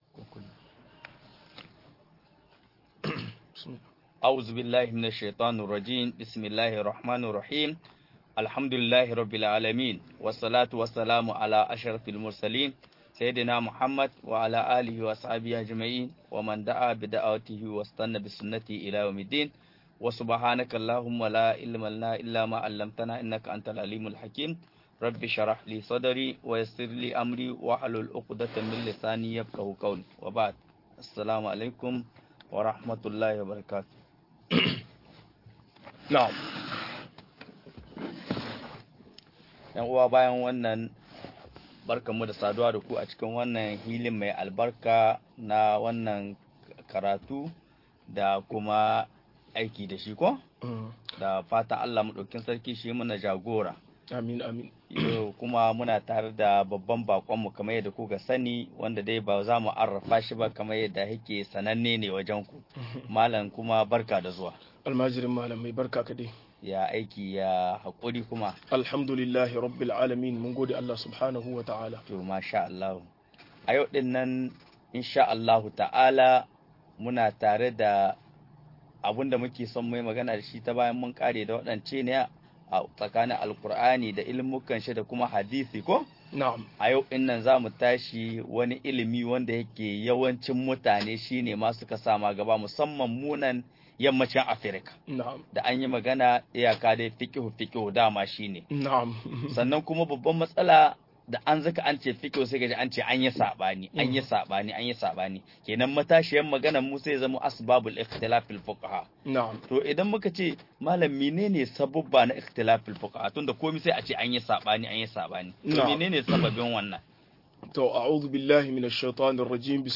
Dalilan saɓanin Malaman fiqhu - MUHADARA